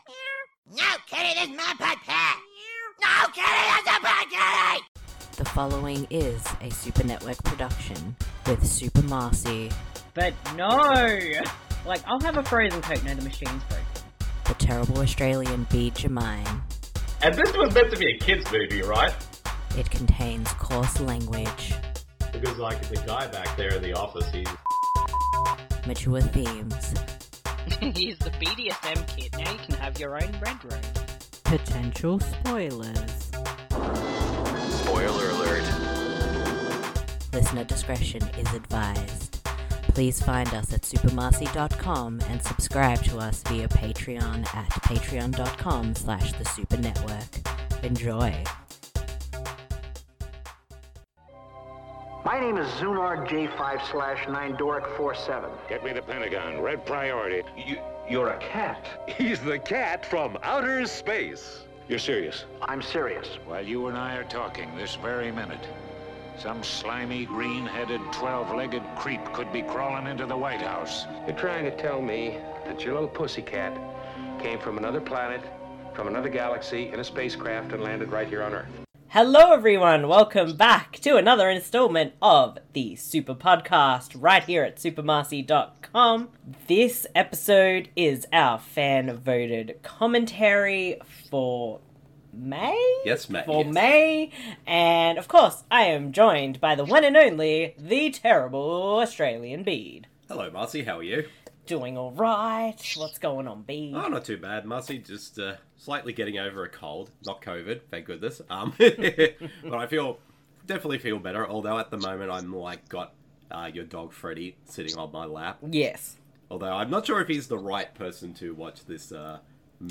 You simply need to grab a copy of the film, and sync up the podcast audio with the film.